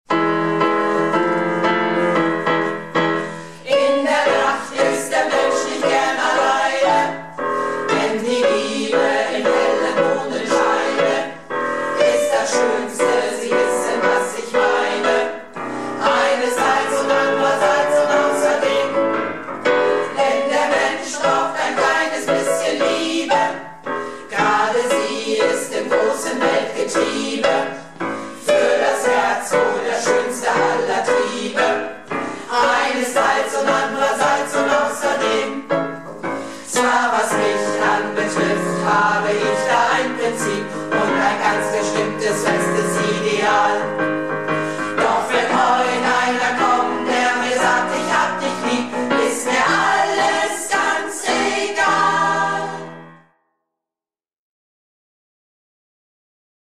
Projektchor "Keine Wahl ist keine Wahl" - Probe am 26.03.19